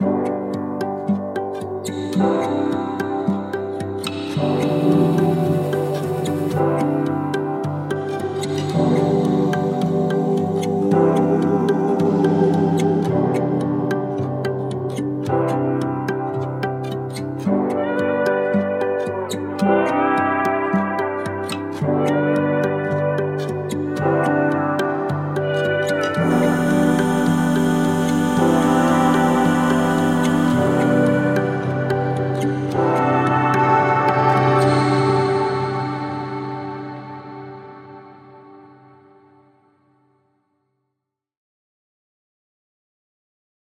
Jingle 2